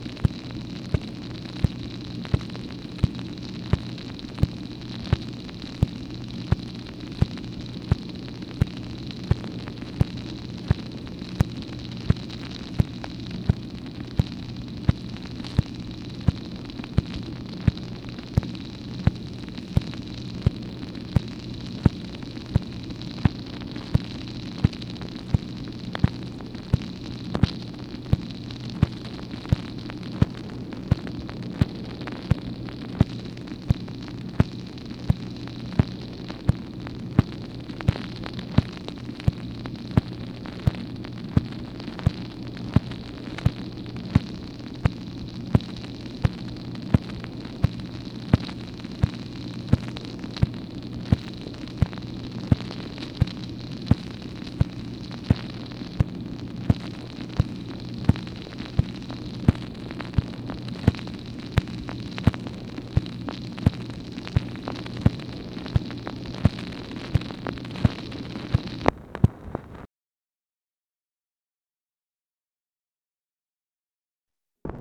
MACHINE NOISE, July 8, 1965
Secret White House Tapes | Lyndon B. Johnson Presidency